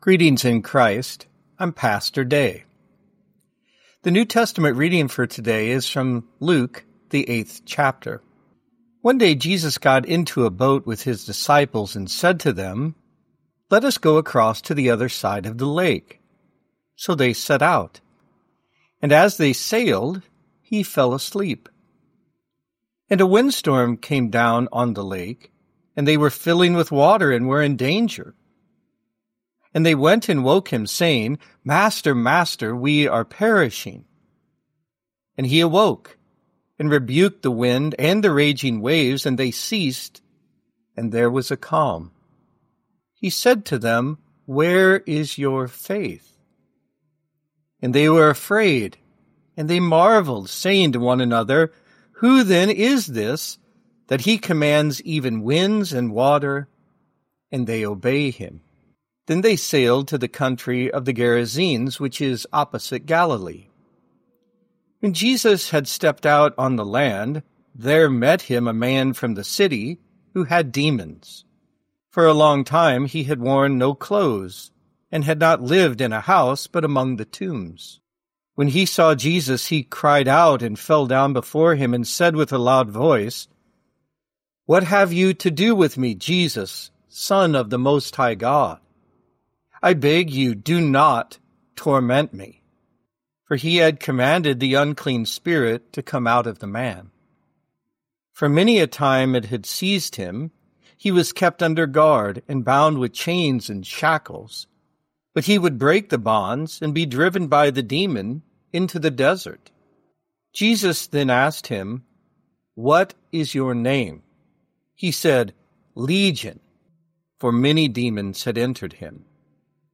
Morning Prayer Sermonette: Luke 8:22-39
Hear a guest pastor give a short sermonette based on the day’s Daily Lectionary New Testament text during Morning and Evening Prayer.